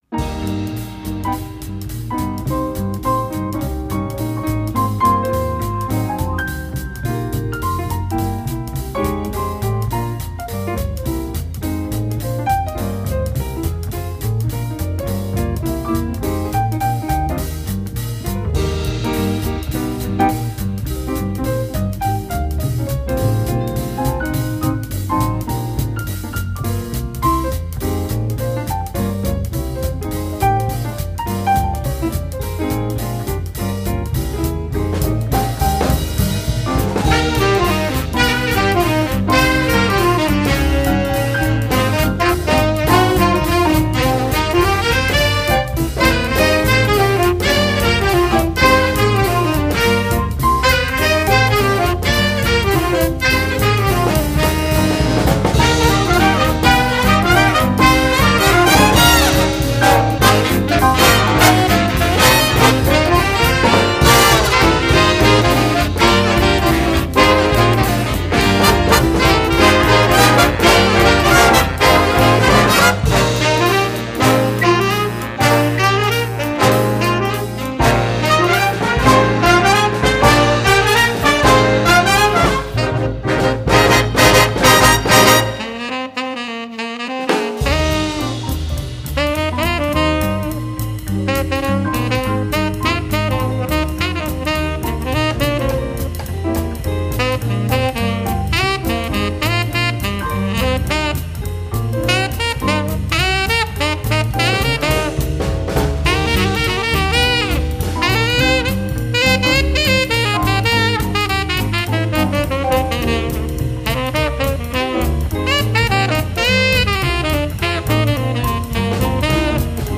Répertoire pour Harmonie/fanfare - Big Band